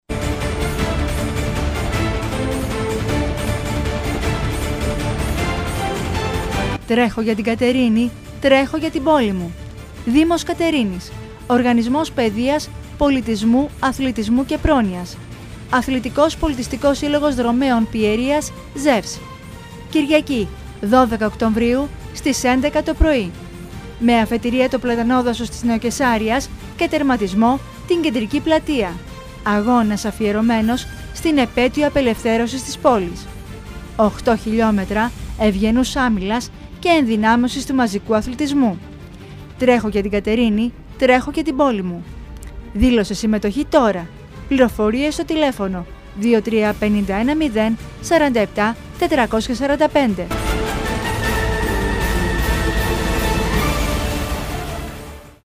ΑΚΟΥΣΤΕ: ΤΡΕΧΩ ΓΙΑ ΤΗΝ ΚΑΤΕΡΙΝΗ SPOT Ως ημερομηνία διεξαγωγής ορίζεται η Κυριακή 12 Οκτωβρίου 2014 και ώρα 11:00 π.μ. Αφετηρία του αγώνα θα είναι το Πλατανόδασος της Νεοκαισάρειας και τερματισμός η κεντρική πλατεία της Κατερίνης (άγαλμα ελευθερίας).